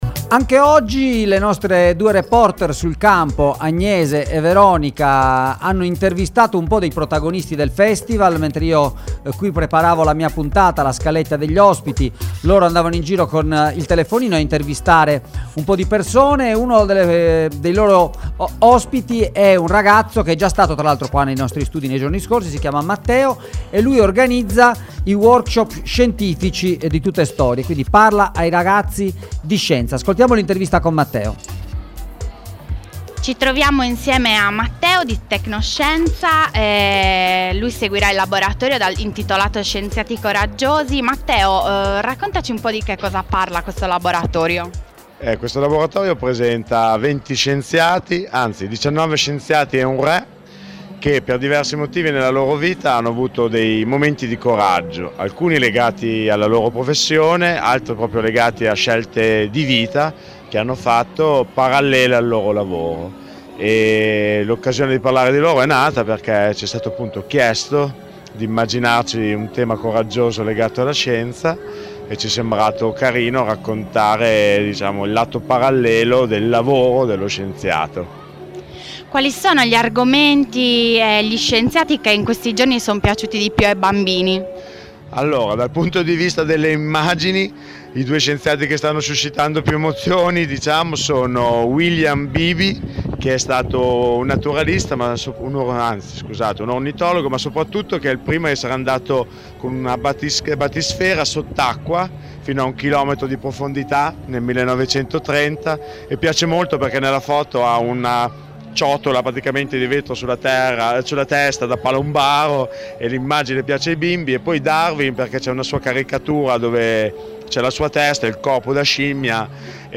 IN DIRETTA DA TUTTESTORIE! / DAY 3
Che cos’è il coraggio? Terzo giorno di Tuttestorie 2016, 11esima edizione del festival di letteratura per ragazzi.